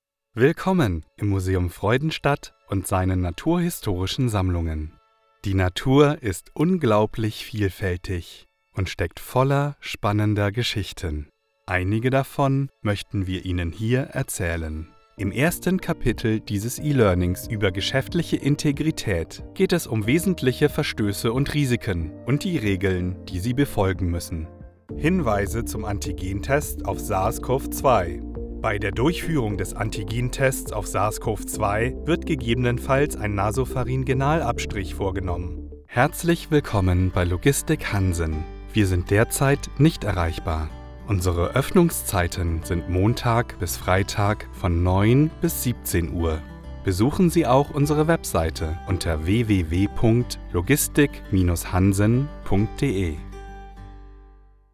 Narrative